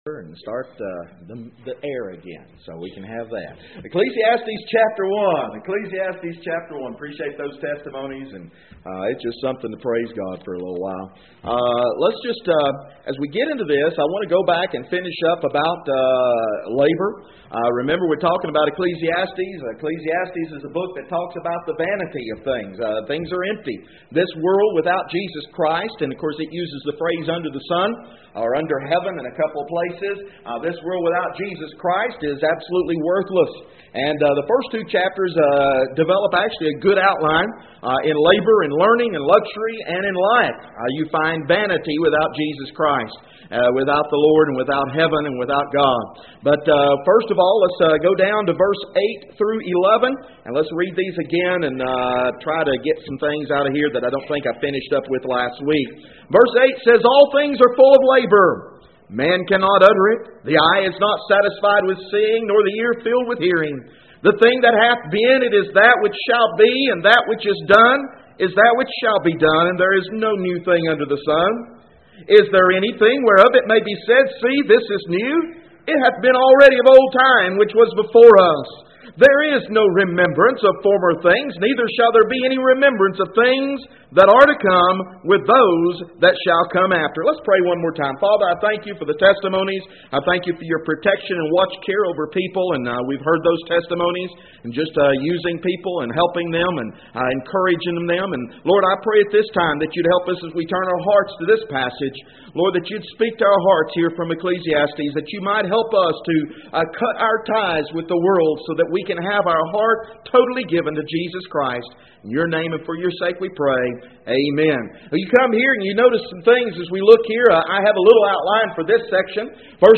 Text: Ecclesiastes 1:8-11 No Outline at this point. For More Information: More Audio Sermons More Sermon Outlines Join the Learn the Bible mailing list Email: Send Page To a Friend